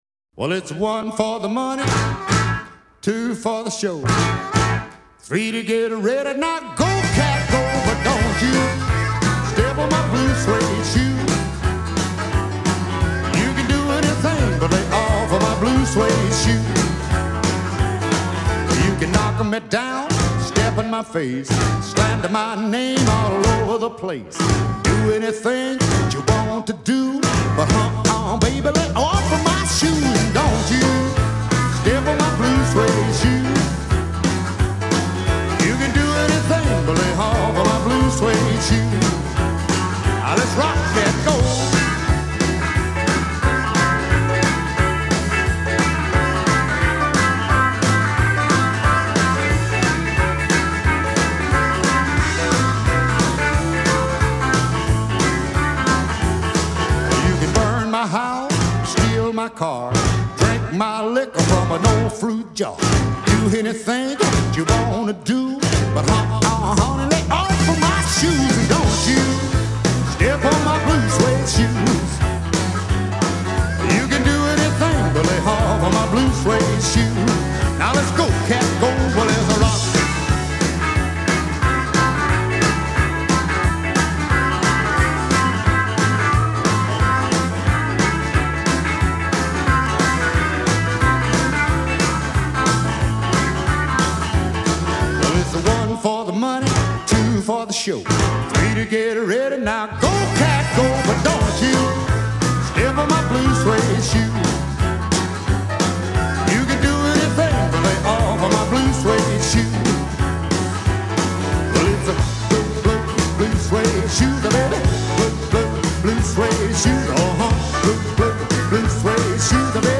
Настоящий рок-н-ролл!